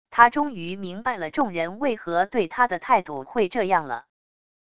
他终于明白了众人为何对他的态度会这样了wav音频生成系统WAV Audio Player